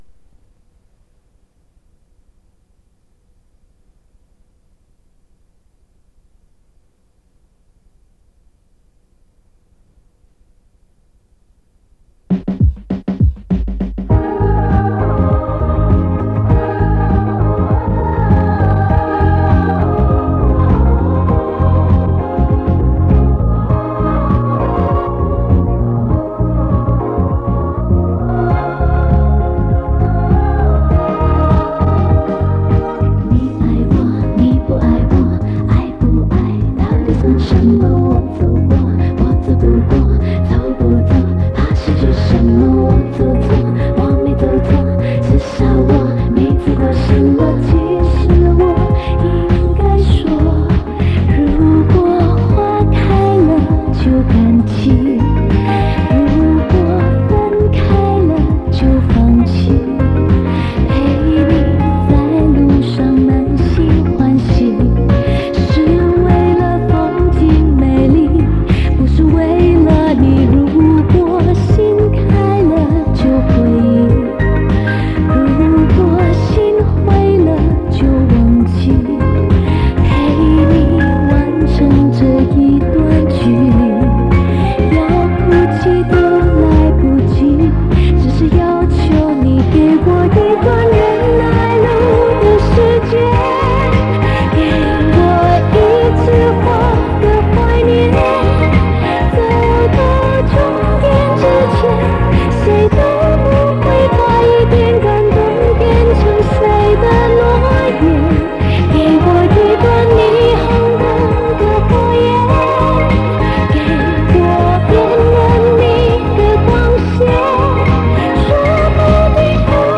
2.因磁带年代已久，有些有偏音及音质一般情况，在所难免，尽量会选好些音质的磁带进行录制。
3.均是立体声录制，戴上耳机效果更佳，推荐采用老式的平头耳机，现代耳机太灵敏，磁带底噪声会较大。
5.音源只展示磁带机播放试听效果，请支持正版音乐。